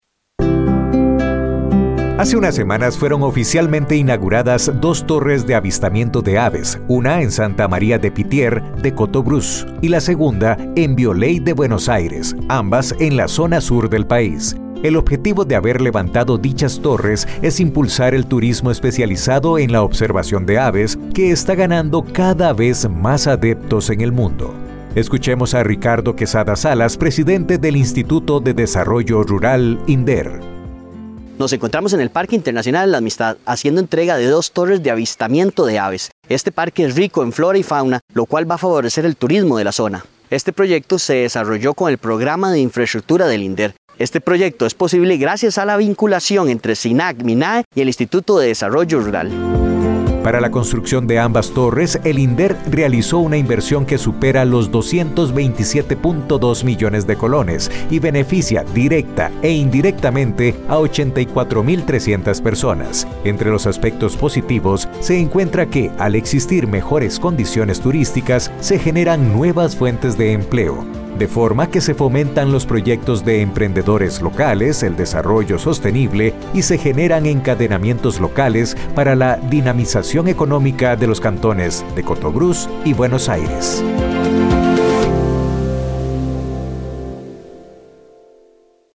Escuchemos a Ricardo Quesada Salas, presidente del Instituto de Desarrollo Rural, INDER.